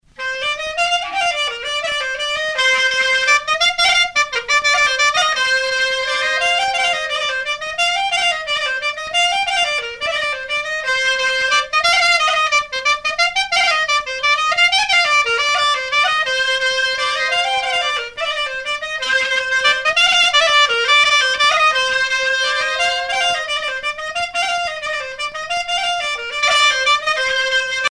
danse : gavotte bretonne